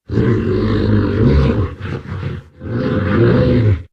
wolf_idle_2.ogg